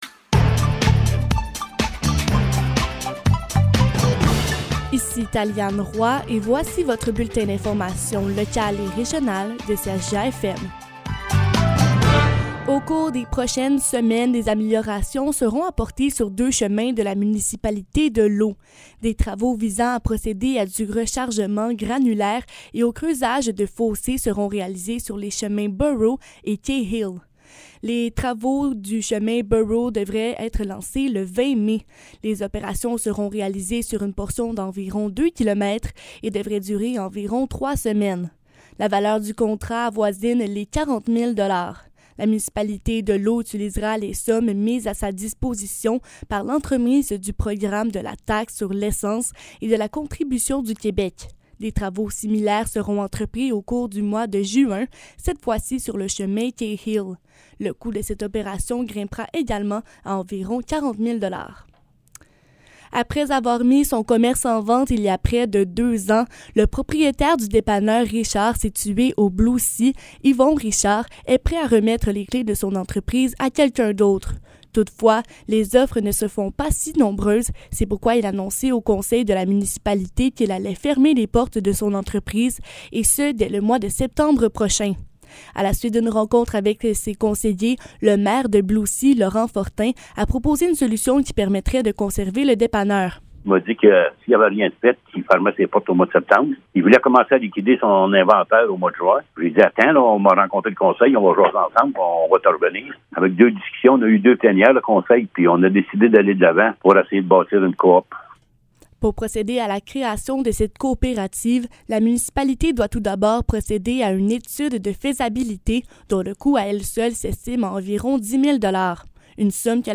Nouvelles locales - 17 mai 2022 - 12 h